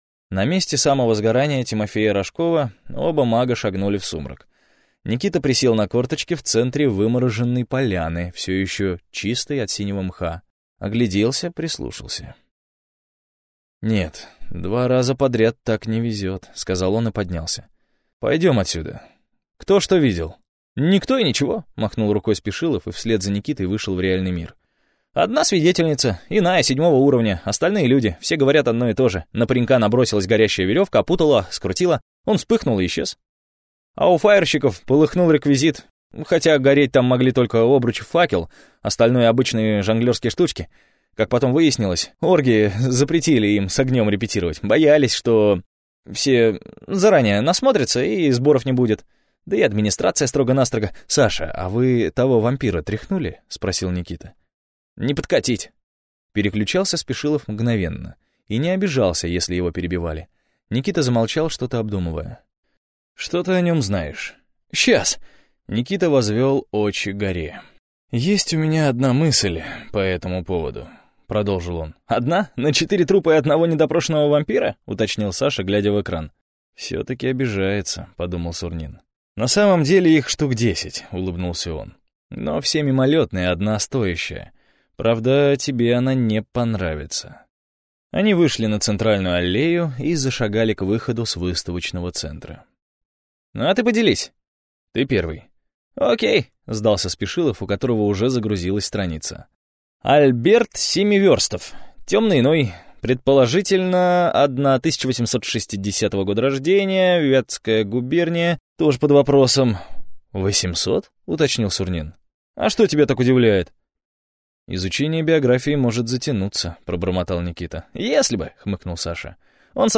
Аудиокнига Оперативный резерв | Библиотека аудиокниг